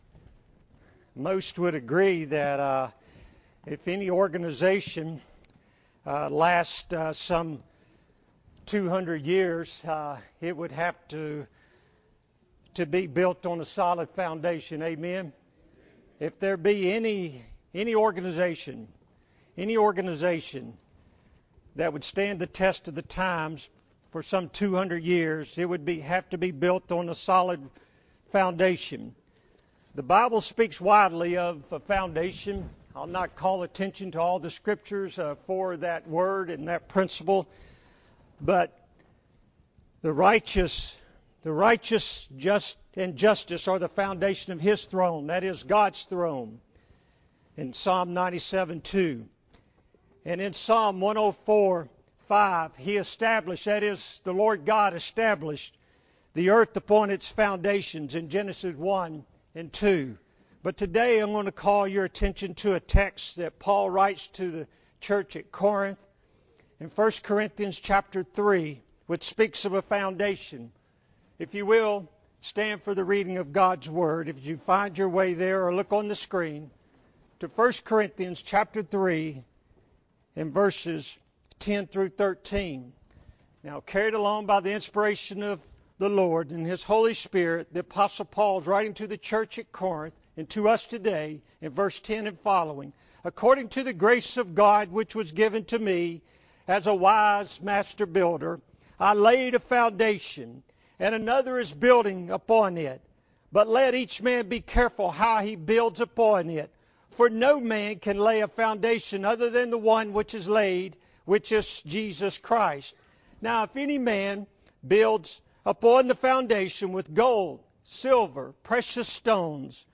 June 11, 2023 – Morning Worship – 200th Anniversary